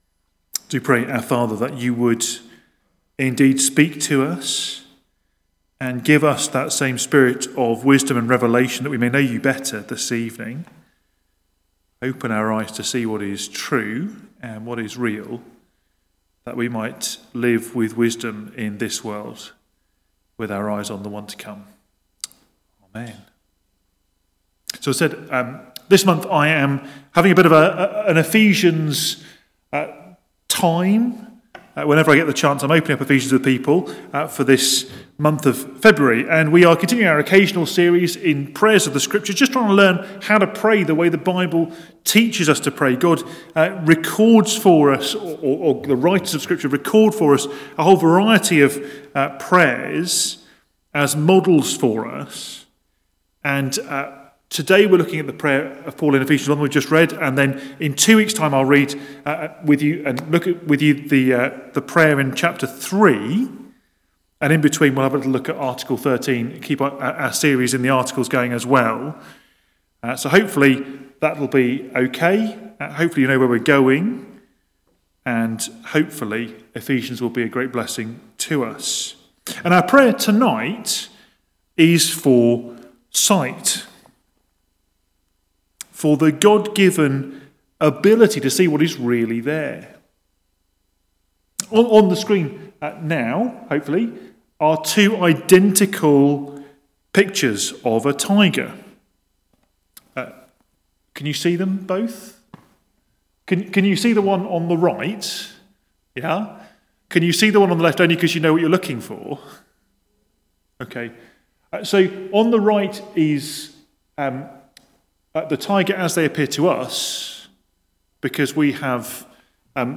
Media Library We record sermons from our Morning Prayer, Holy Communion and Evening services, which are available to stream or download below.
Passage: Ephesians 1:15-23 Series: Prayers of the Church Theme: Sermon Search